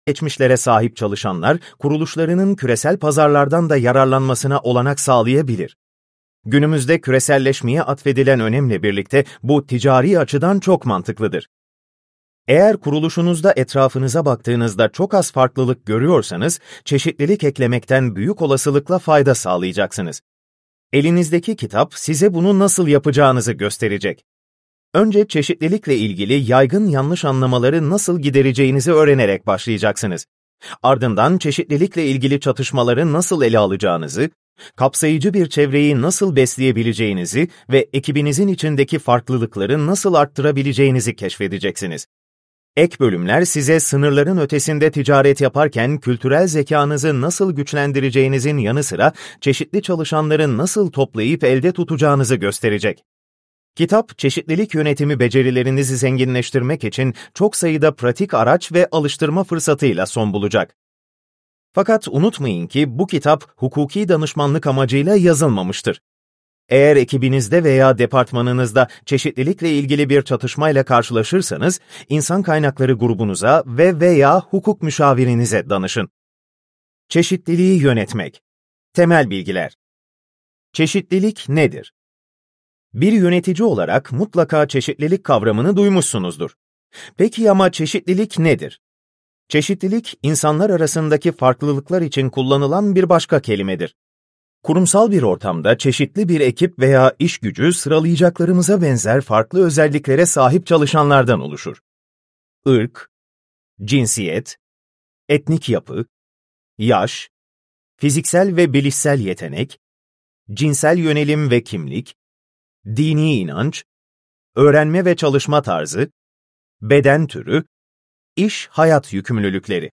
Çeşitliliği Yönetmek - Seslenen Kitap